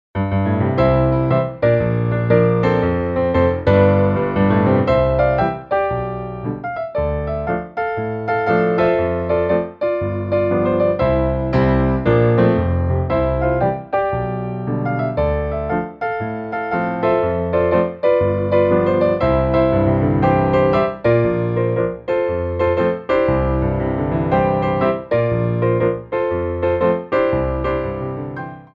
Piano Arrangements of Pop & Rock for Ballet Class
4/4 (16x8)